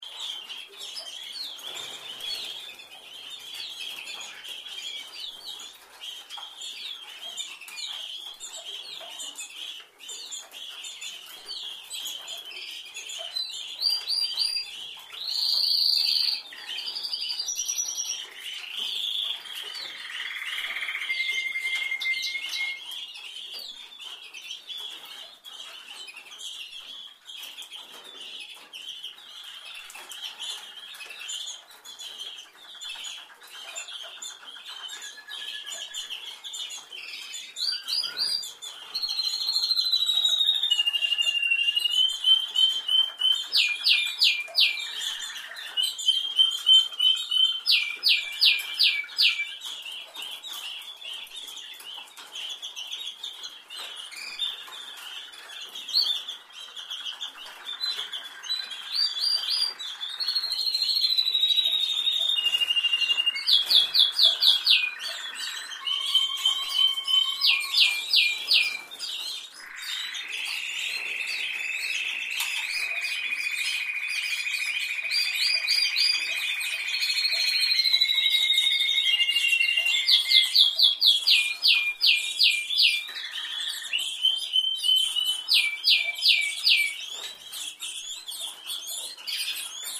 دانلود صدای قناری از ساعد نیوز با لینک مستقیم و کیفیت بالا
جلوه های صوتی
برچسب: دانلود آهنگ های افکت صوتی انسان و موجودات زنده